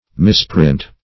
misprint - definition of misprint - synonyms, pronunciation, spelling from Free Dictionary
Misprint \Mis*print"\ (m[i^]s*pr[i^]nt"), v. t.
misprint \mis"print\ (m[i^]s"pr[i^]nt), n.